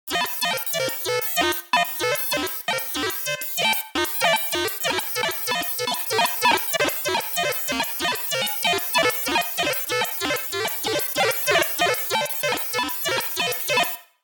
PC処理音
フリー音源効果音「PC処理音」です。